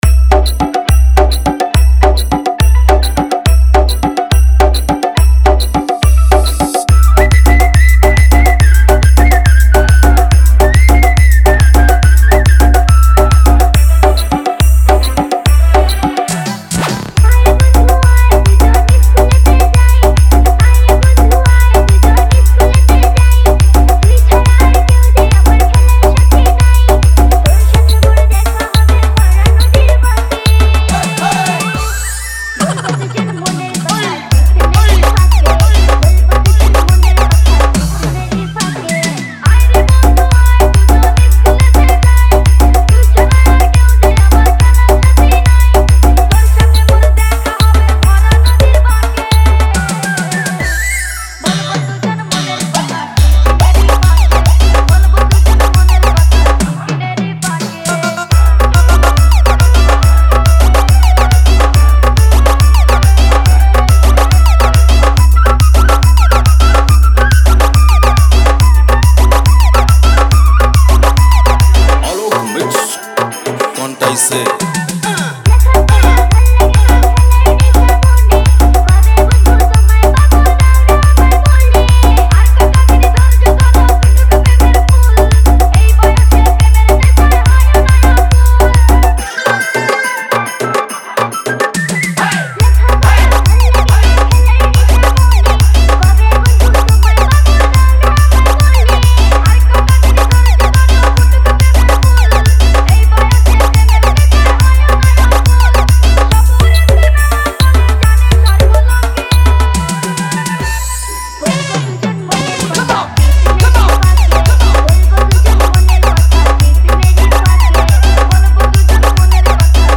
দুর্গা পূজা স্পেশাল নাইট স্পেশাল মাতাল ডান্স মিক্স 2024